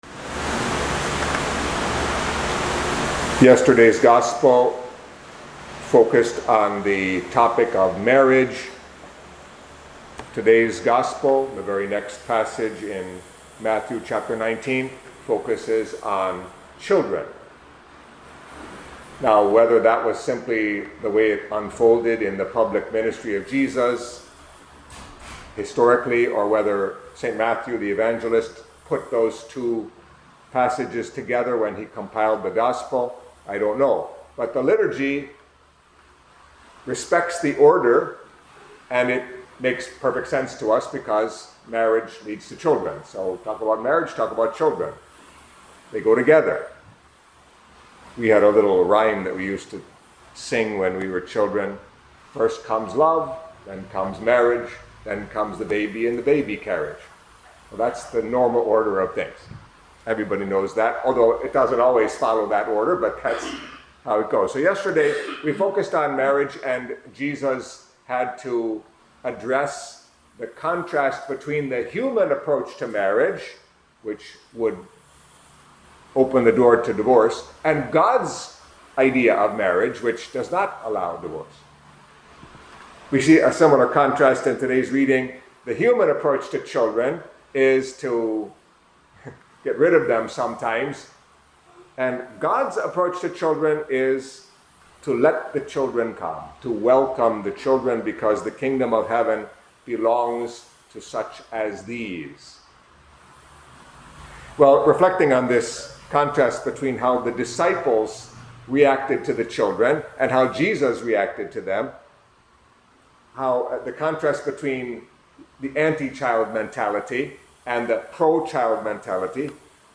Catholic Mass homily for Saturday of the Nineteenth Week of Ordinary Time